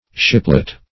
Shiplet \Ship"let\